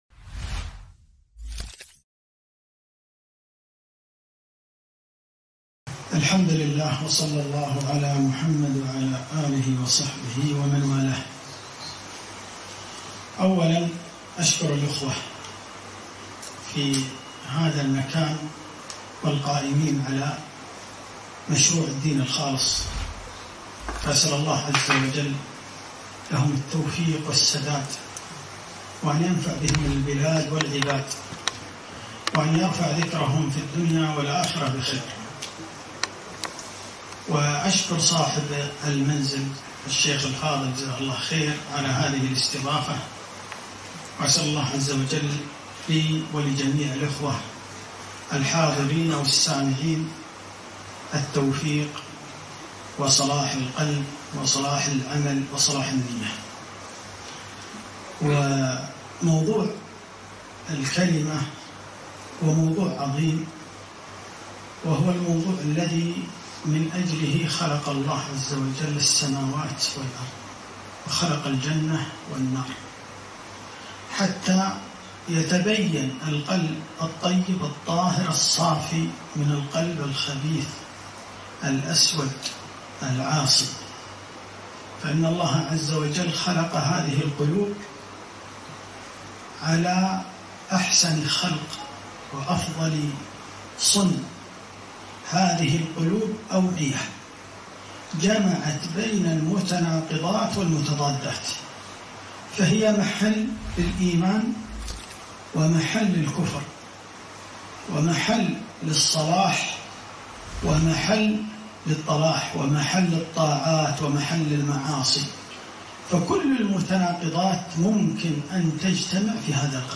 محاضرة - من أجله خلق الله السموات والأرض